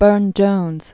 (bûrnjōnz), Sir Edward Coley 1833-1898.